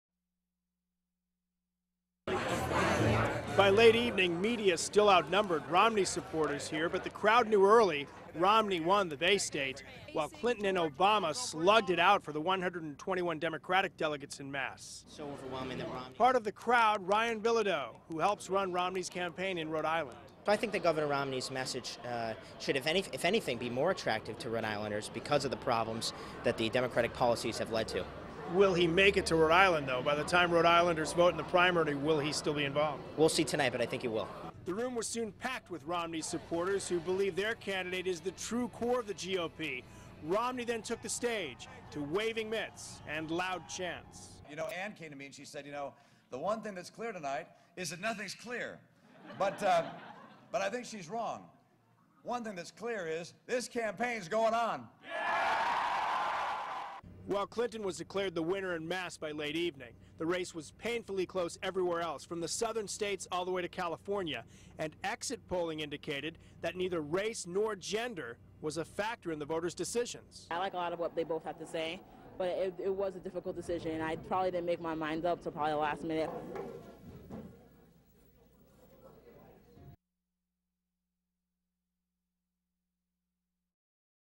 Interview with WPRI 12 Providence